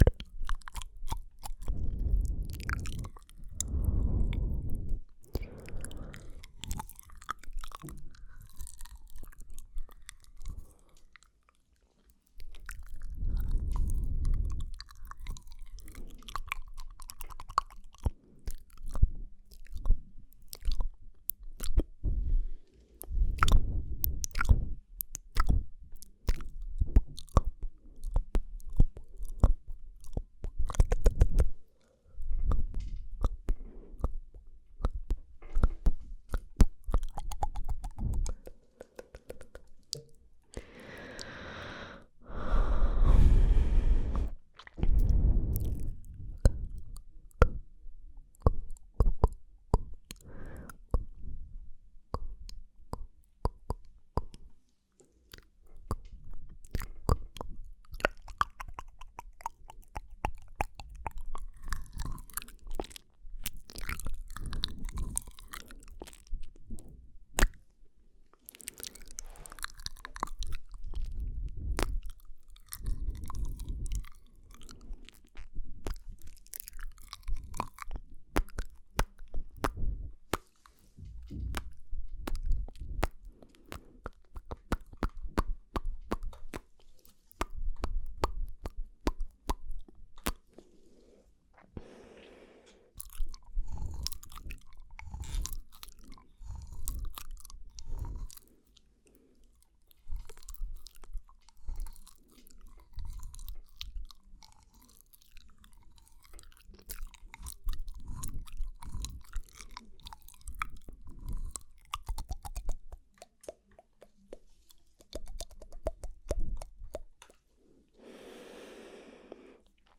Свист, щелчки языком, цоканье, шепот, мычание и другие необычные звуковые эффекты.
Звуки изо рта